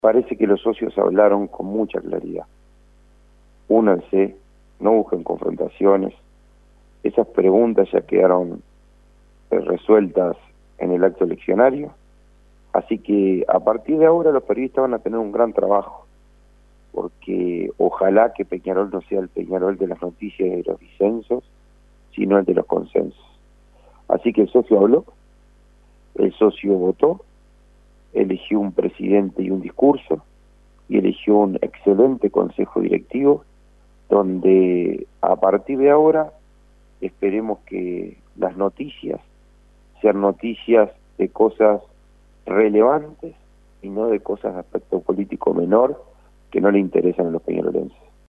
En diálogo con informativo 810